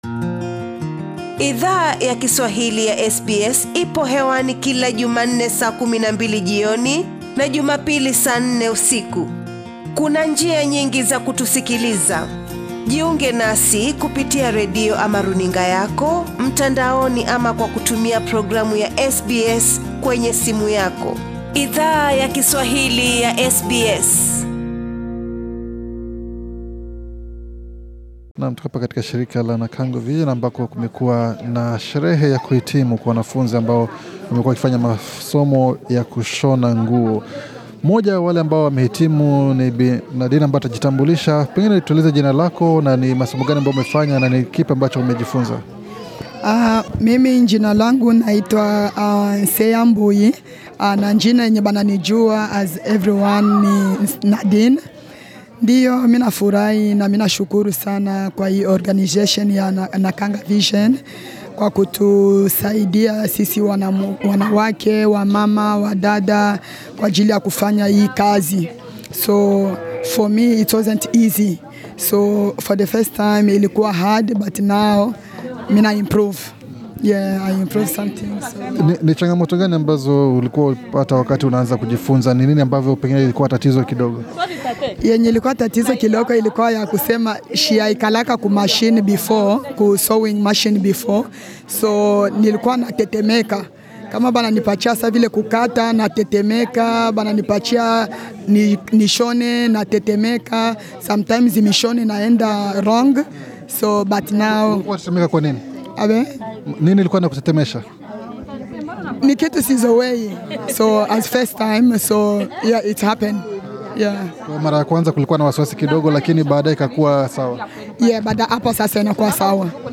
SBS Swahili ilizungumza na baadhi ya wanafunzi walio hitimu katika masomo hayo yakushona, walitueleza jinsi ujuzi huo mpya wakushona utawasaidia kushiriki katika soko la ajira.